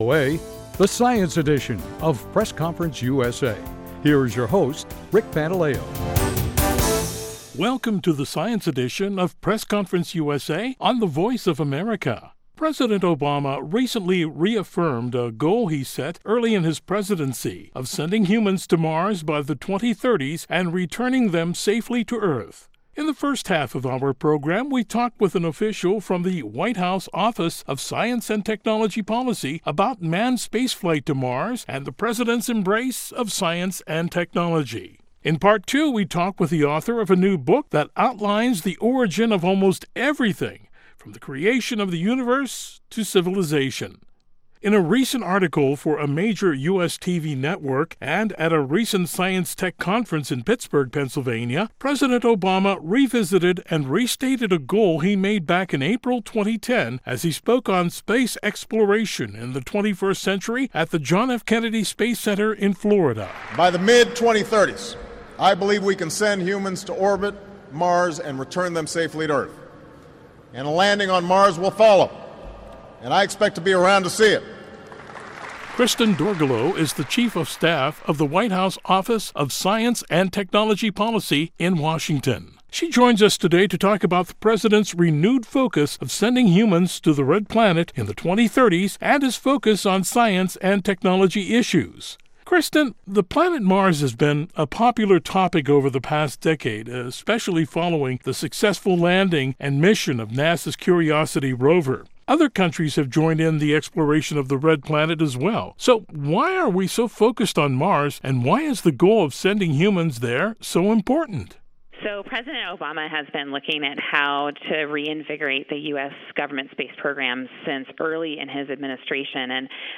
He’ll also talk with the author of a new book that outlines the origin of almost everything from the universe to civilization.